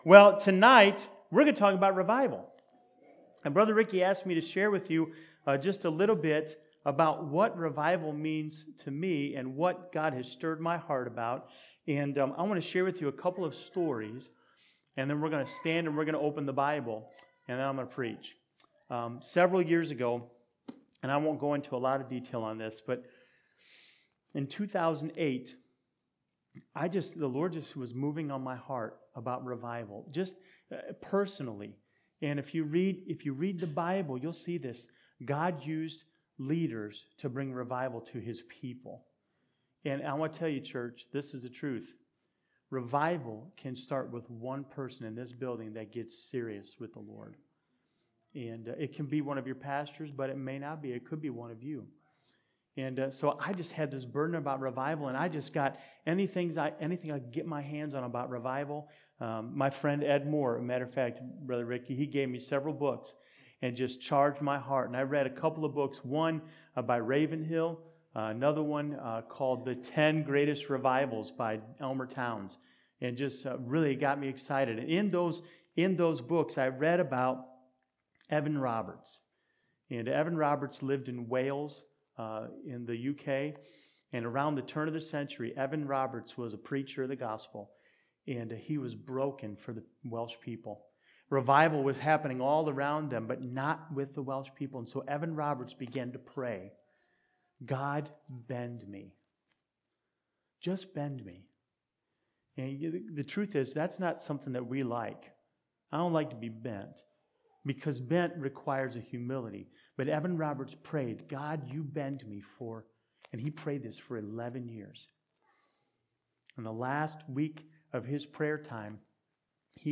This Sunday evening revival sermon was recorded on October 2, 2018.